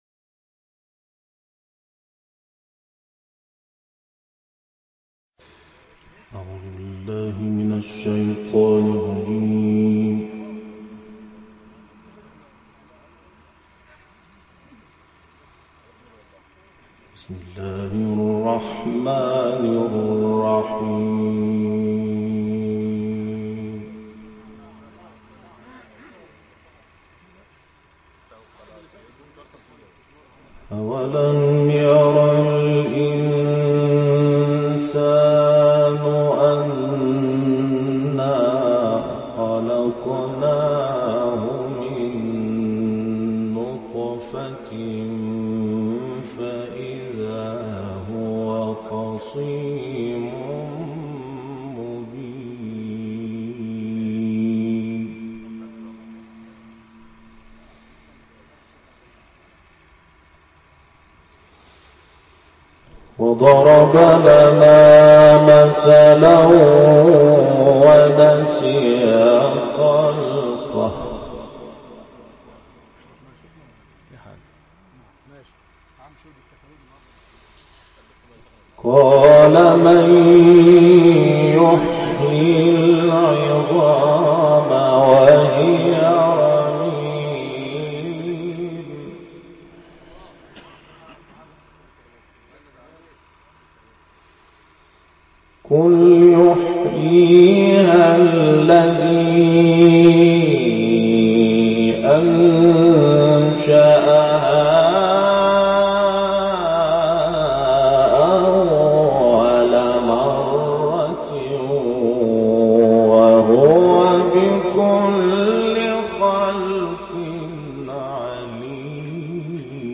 تلاوت جدید «محمود شحات انور» از سوره یس
گروه شبکه اجتماعی: محمود شحات انور، در جدیدترین تلاوت خود در مصر، آیاتی از سوره‌های مبارکه یس، انفطار و شمس را تلاوت کرده است.